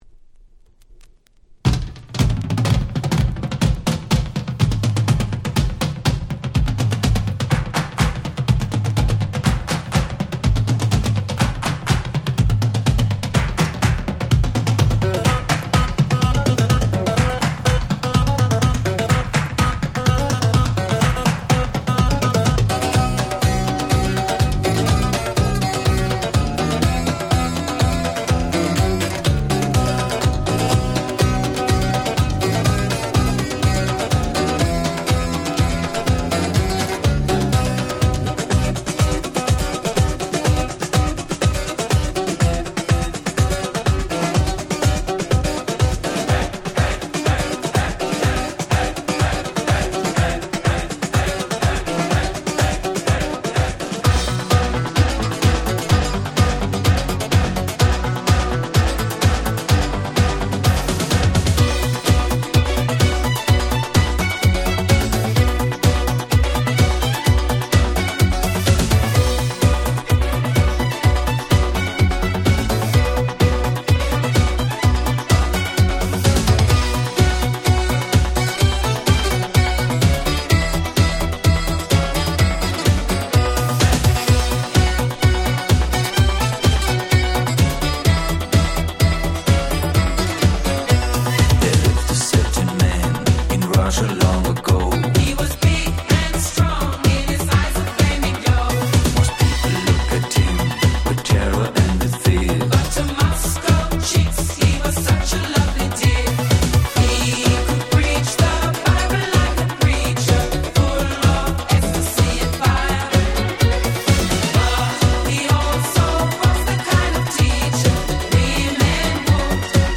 89' Nice Remix !!
問答無用のDisco Classics !!
89年のRemixと言う事もあり曲調はDisco + Houseと言った感じで大変フロア向け。